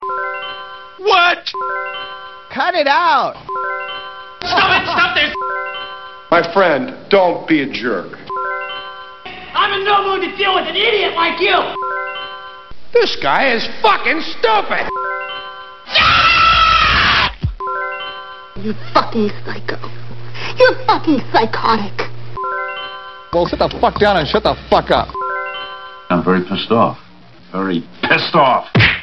aol-message_3211.mp3